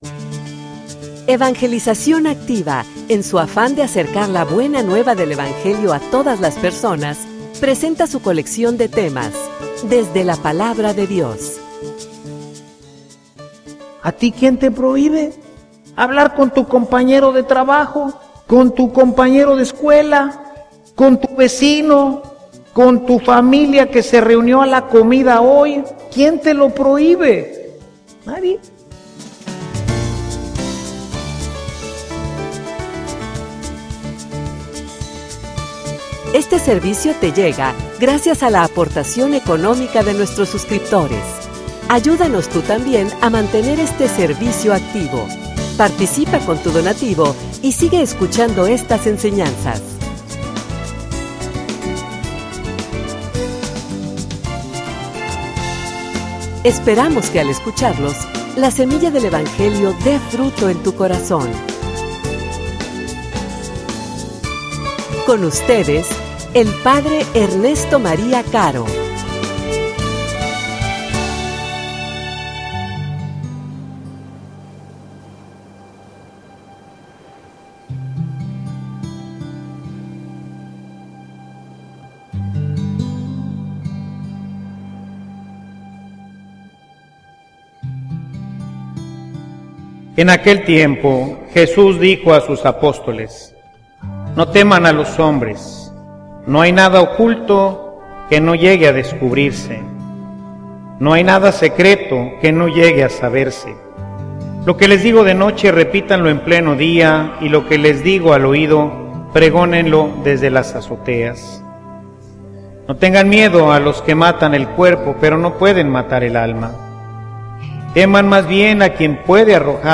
homilia_Habra_consecuencias.mp3